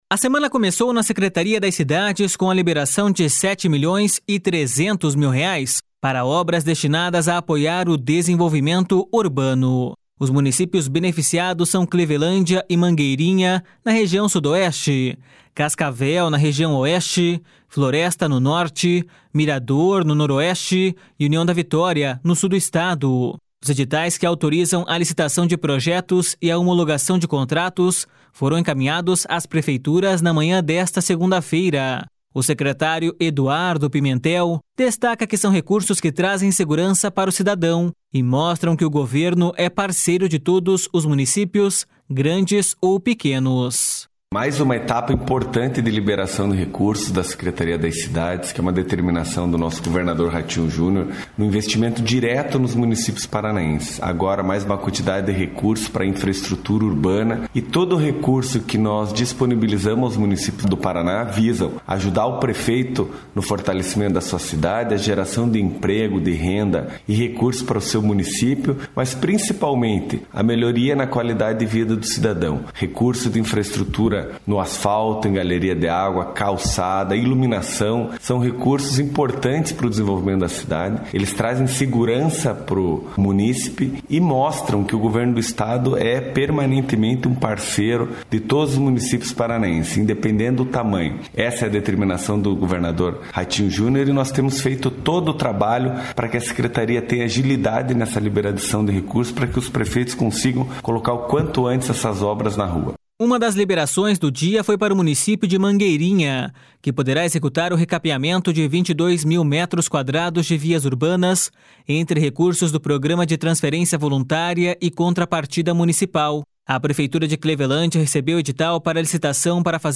O secretário Eduardo Pimentel destaca que são recursos que trazem segurança para o cidadão e mostram que o Governo é parceiro de todos os municípios, grandes ou pequenos.// SONORA EDUARDO PIMENTEL.//